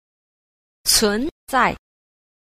10. 存在 – cúnzài – tồn tại
Cách đọc: